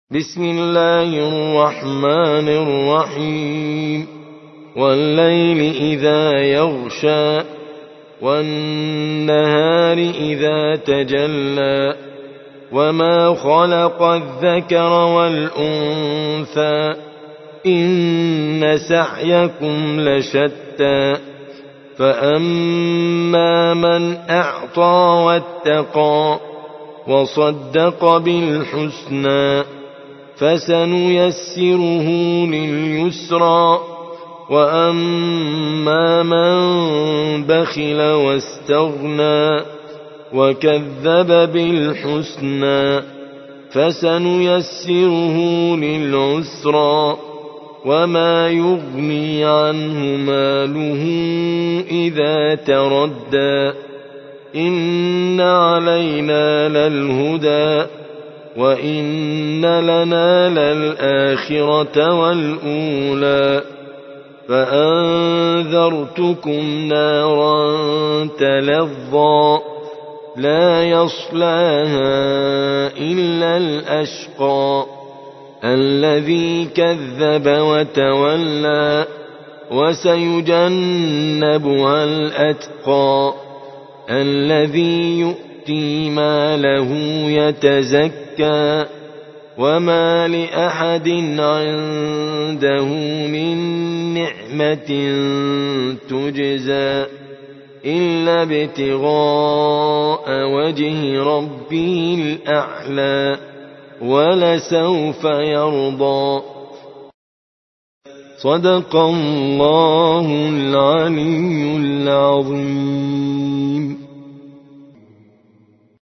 92. سورة الليل / القارئ